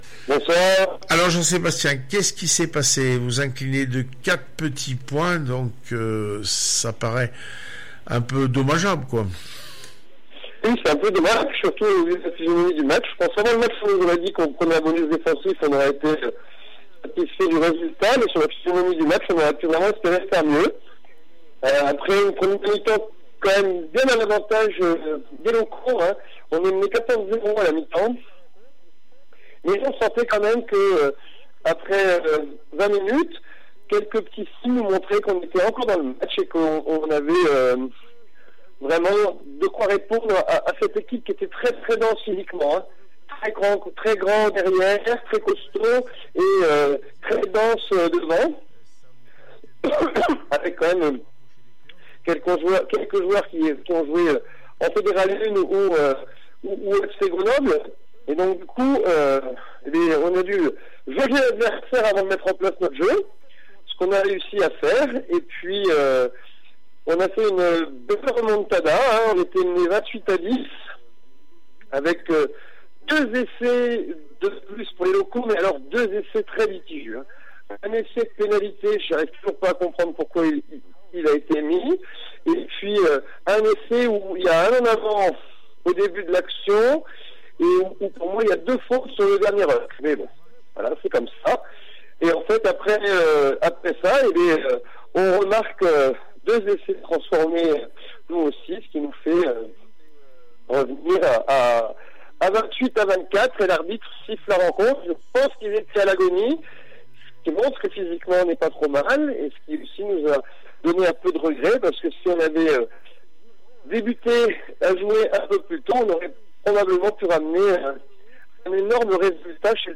18 février 2020   1 - Sport, 1 - Vos interviews, 2 - Infos en Bref   No comments